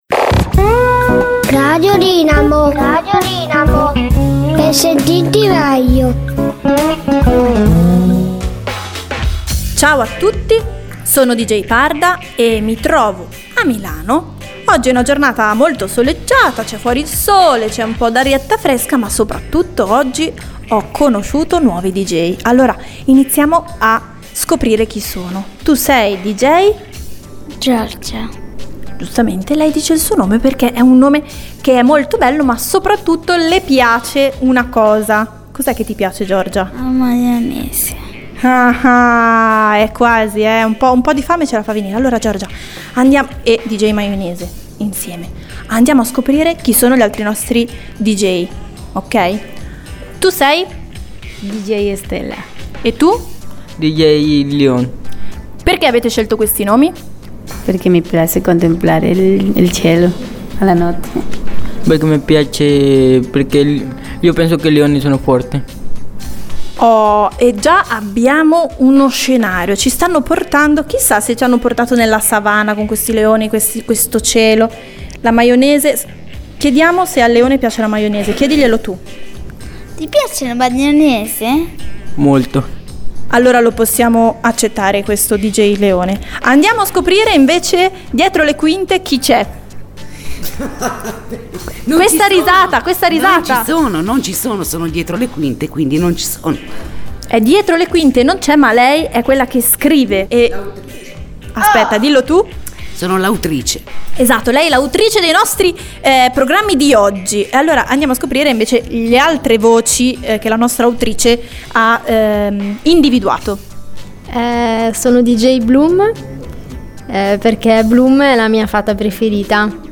INTERVISTA A TUTTI I DJ!!